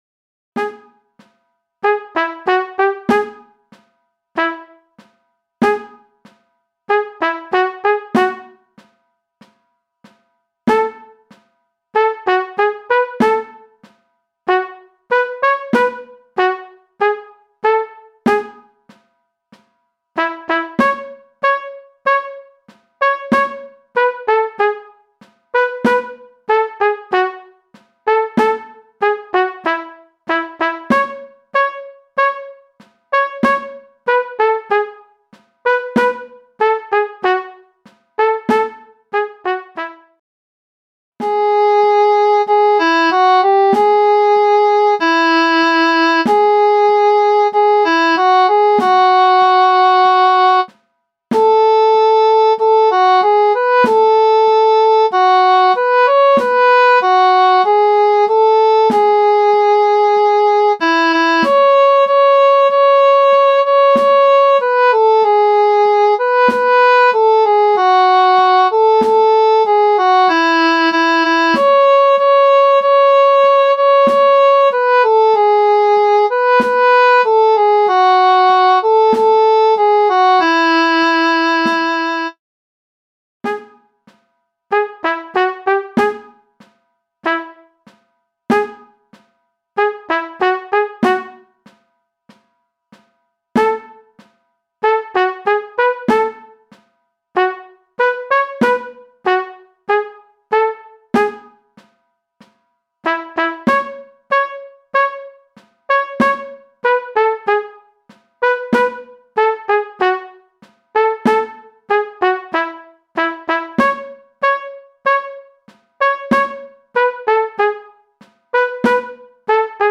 MIDI - 1-stimmig